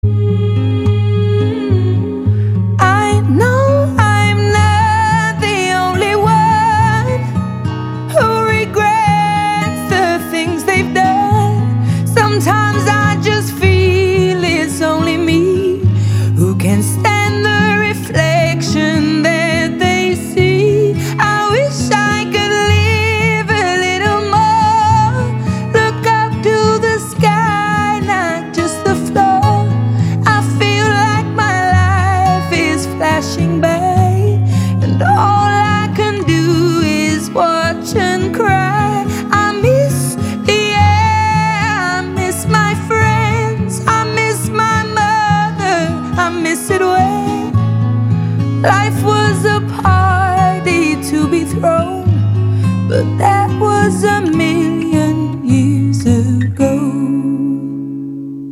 • Качество: 192, Stereo
красивые
лирика
романтика
румба
Композиция в ритме румбы